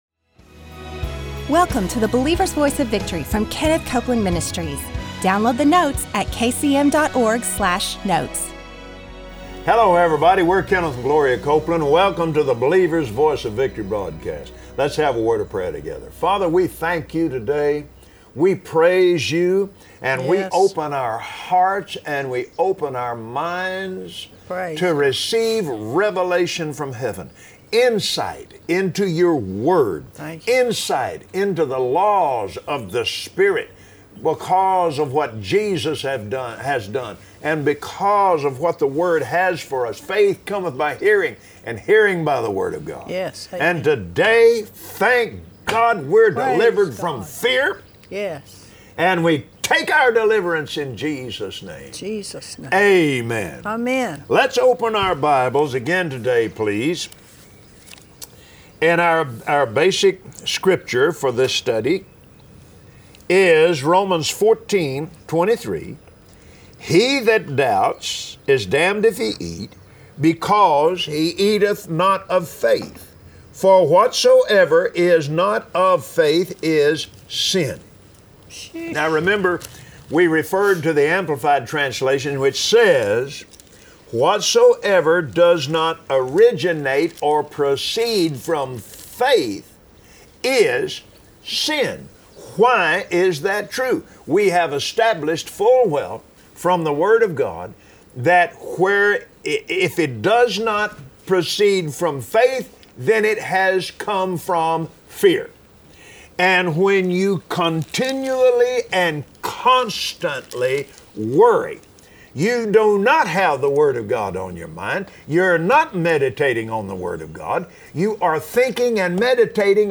Believers Voice of Victory Audio Broadcast for Friday 07/08/2016 Today, on the Believer’s Voice of Victory, Kenneth and Gloria Copeland teach why it is necessary for cast your cares onto the Lord. God’s plan is the best and He is able to fix whatever it is that is broken.